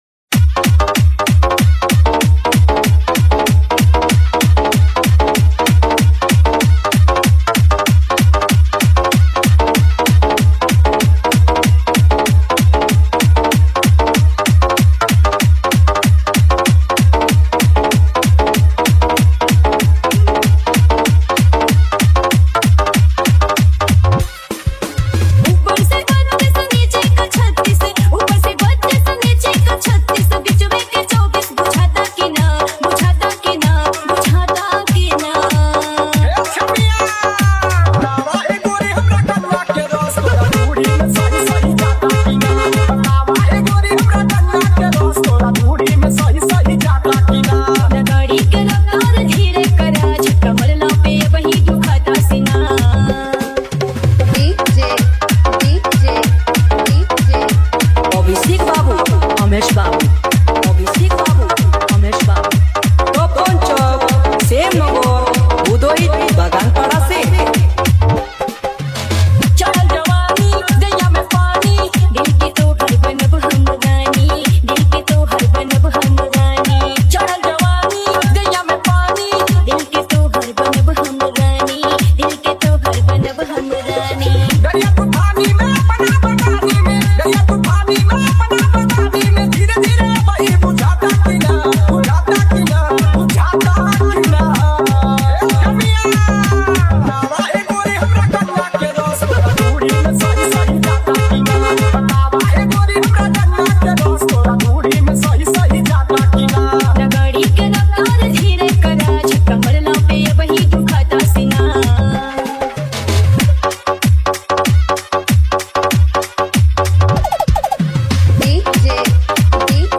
Dj Remixer
New Bhojpuri DJ Songs